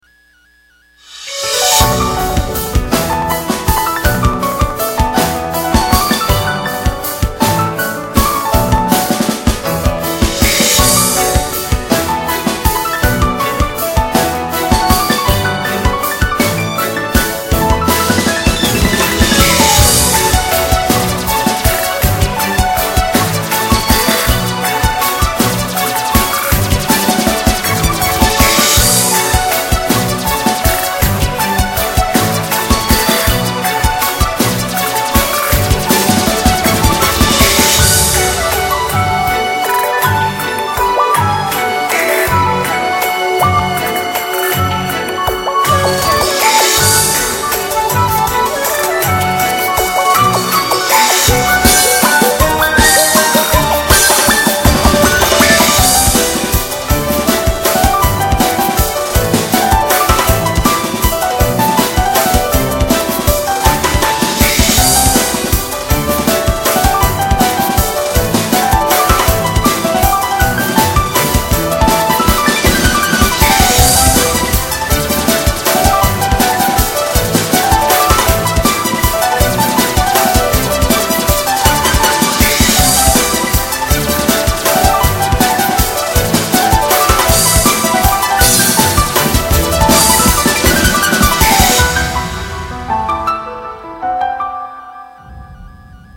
퓨젼에 가까운 쪽은 아니지만 피아노 선율이 아름답네요.